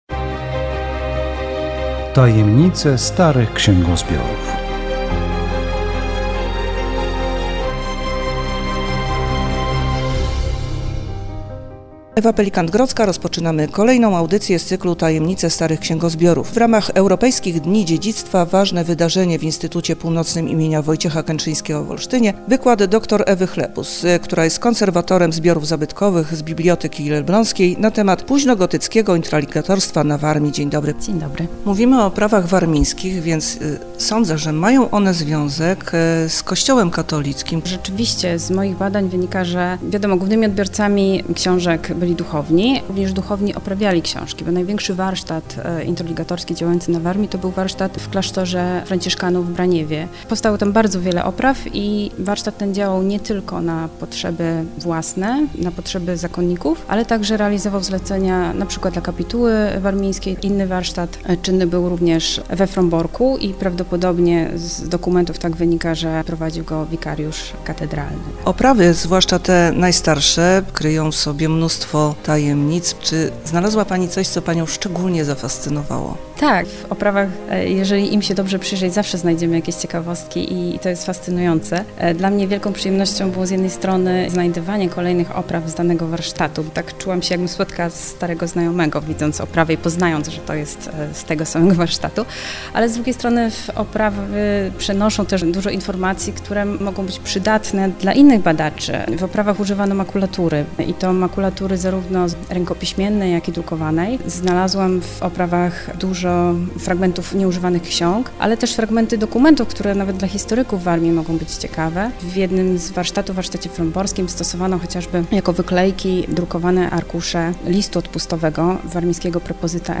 Audycja radiowa "Tajemnice starych księgozbiorów
W audycji rozmowa